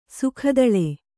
♪ sukhadaḷe